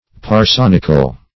Search Result for " parsonical" : The Collaborative International Dictionary of English v.0.48: Parsonic \Par*son"ic\, Parsonical \Par*son"ic*al\, a. Of or pertaining to a parson; clerical.